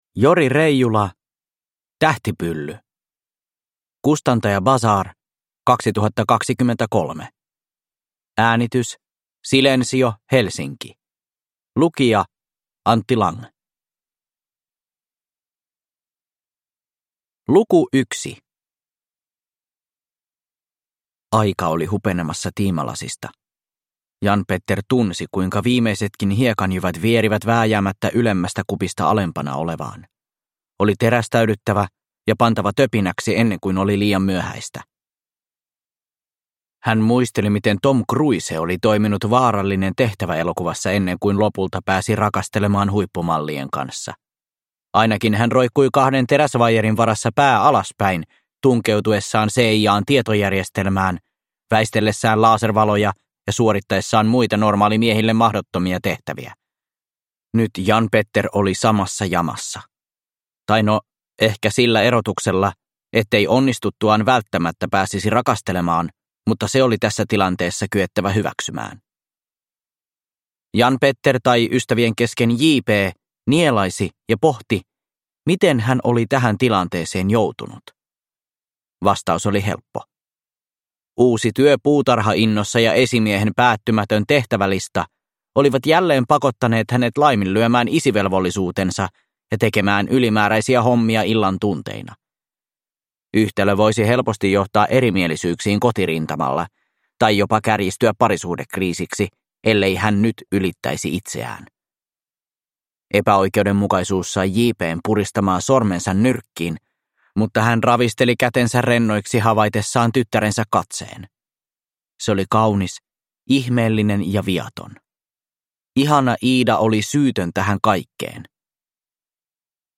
Tähtipylly – Ljudbok